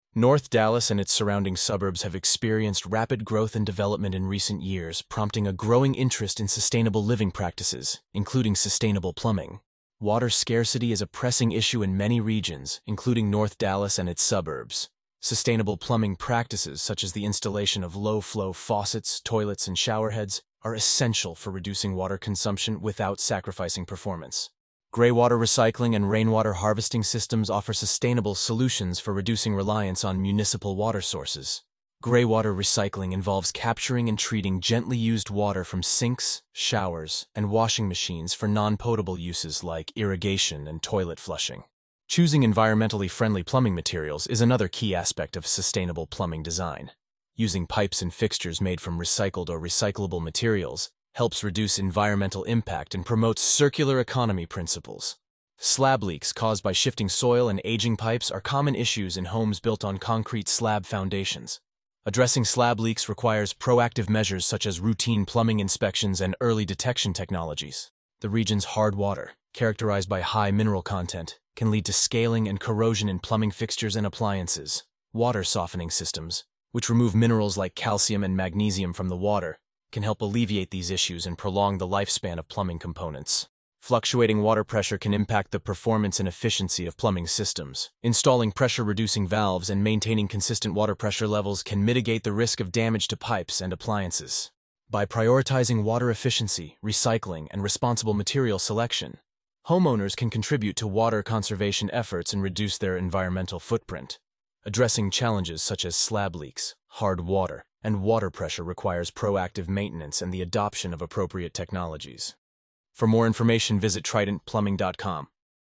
Discover how innovative plumbing solutions contribute to water conservation, energy efficiency, and overall environmental impact. We'll discuss technologies like low-flow fixtures, greywater systems, and rainwater harvesting, and how they can transform your home into a model of sustainability. Join us for an enlightening conversation with plumbing experts and eco-friendly home designers as we explore practical tips and cutting-edge strategies for creating a greener, more sustainable living space.